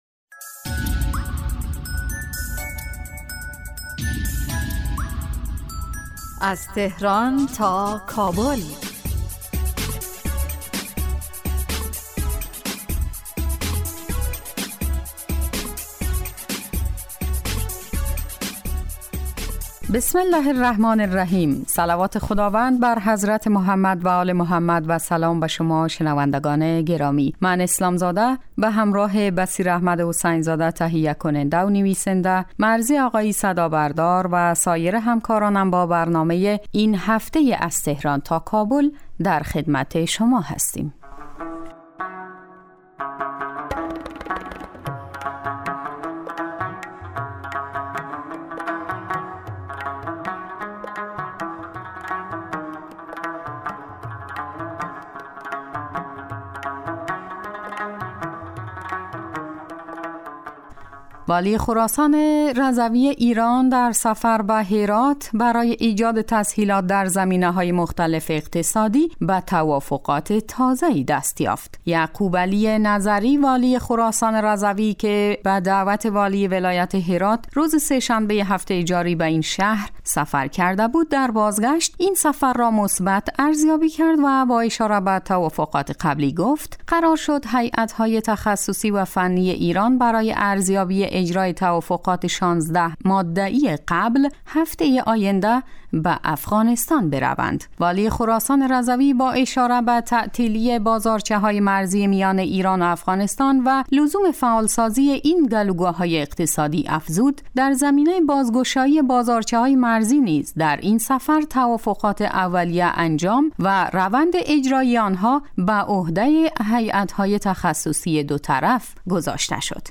برنامه از تهران تا کابل به مدت 15 دقیقه روز جمعه در ساعت 06:30 بعد از ظهر (به وقت افغانستان) پخش می شود. این برنامه به رویدادهای سیاسی، فرهنگی، اقتصادی و اجتماعی مشترک ایران و افغانستان می پردازد.